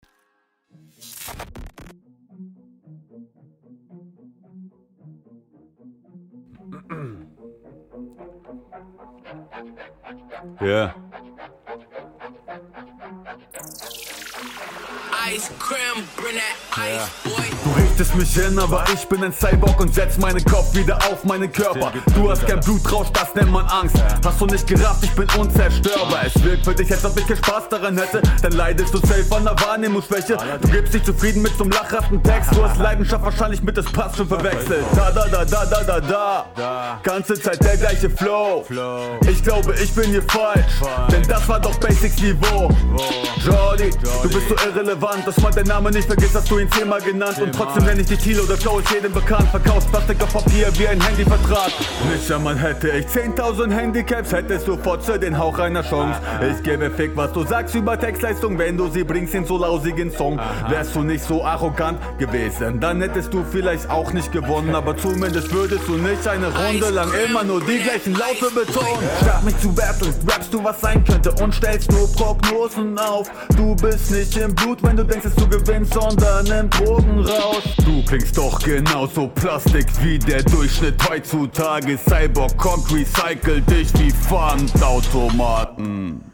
Stimmeinsatz und Flow finde ich deutlich angenehmer und auch runder.